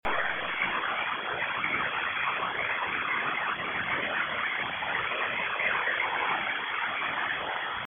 IC746PRO//шумадав